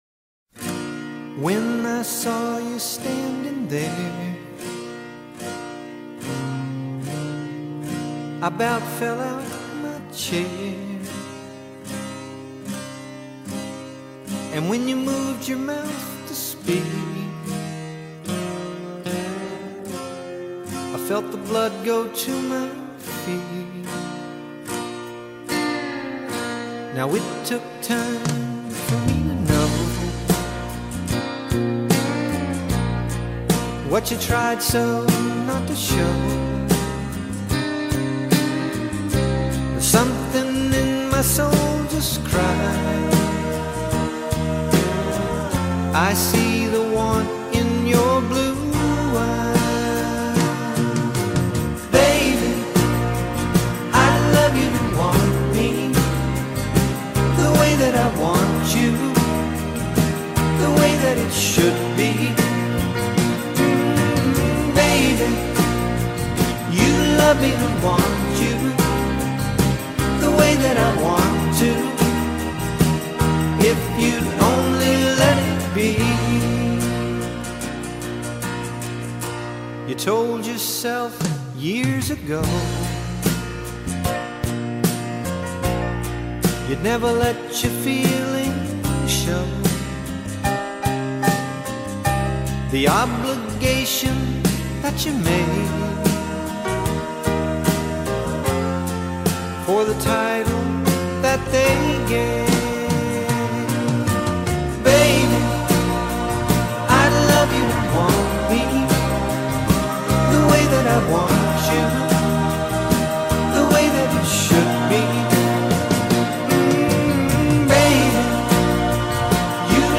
Audio Clip from the Tutorial
Capo 10th - 4/4 Time